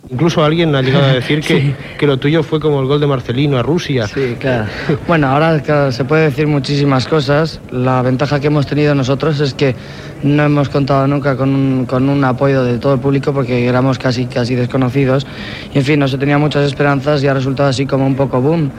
Entrevista a l'esquiador Francisco Fernández Ochoa, medalla d'or a l'olimpíada de Sapporo.
Esportiu
Extret de Crònica Sentimental de Ràdio Barcelona emesa el dia 29 d'octubre de 1994.